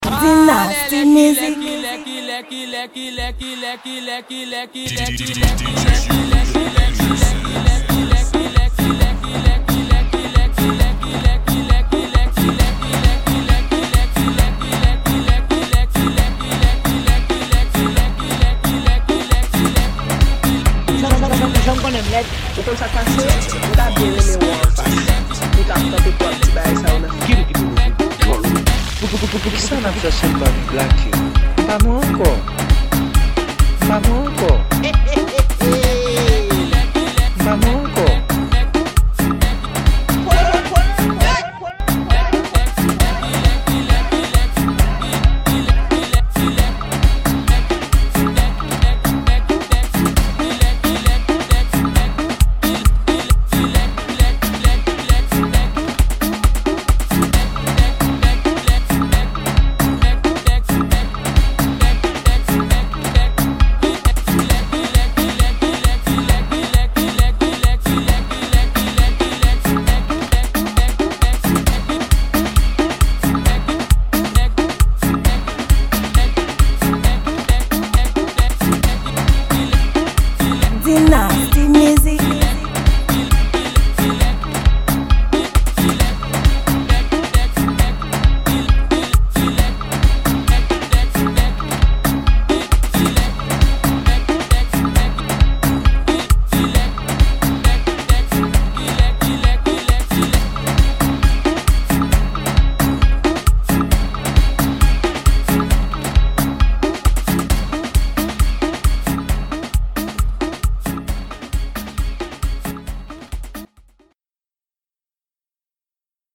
Genre: Remix.